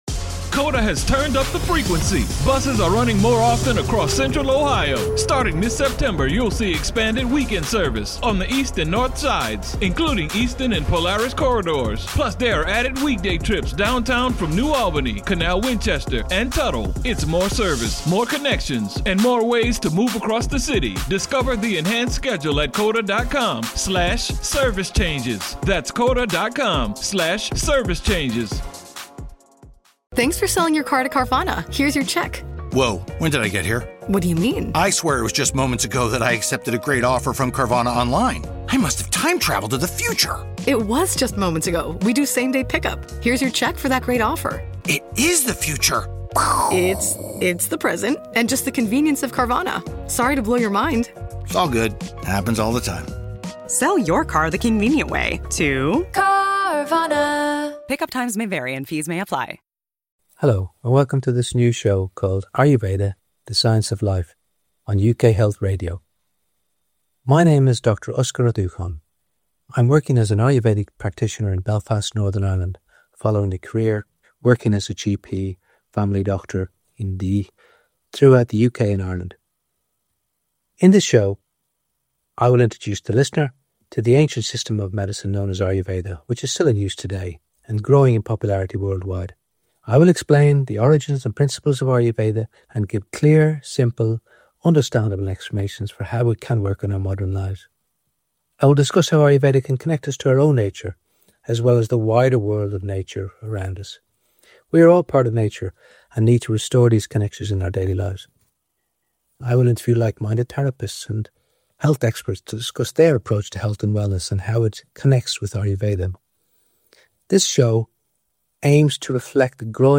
I'll interview therapists and health experts who share a holistic view of wellness.